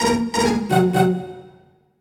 level complete.ogg